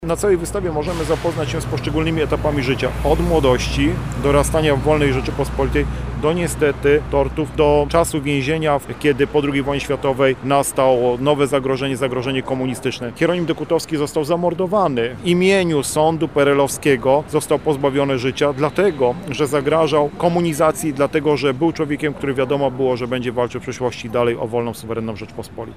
Robert Derewenda-mówi Dyrektor Oddziału Instytutu Pamięci Narodowej w Lublinie dr Robert Derwenda.